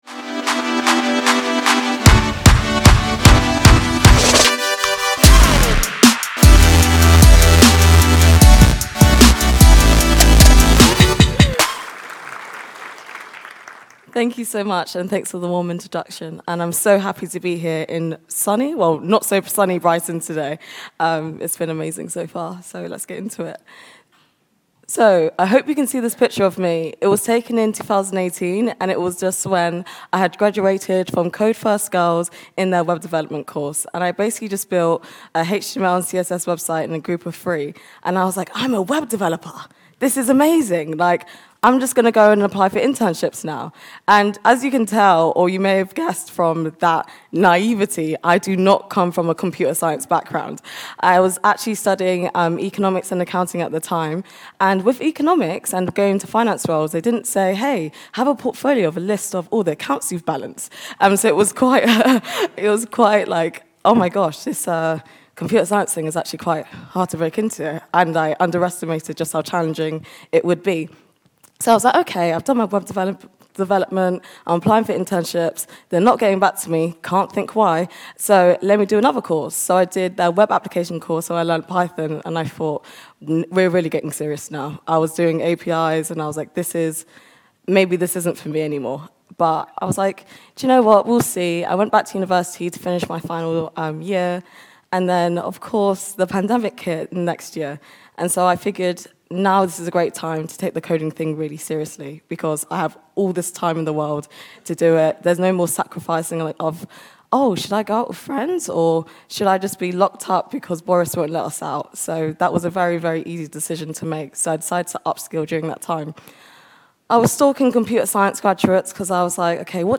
Dealing with imposter syndrome, perfectionism and building a growth mindset - this talk is essentially a survival guide on how to cope when we feel inferior in the tech space where we're surrounded by people doing incredible things both in and outside of work time.